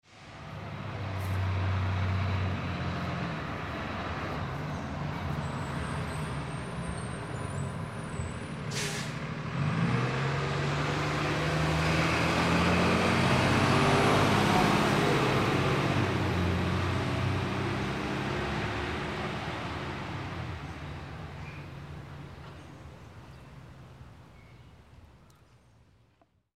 Звуки мусоровоза
Звук подъезжающего и уезжающего мусоровоза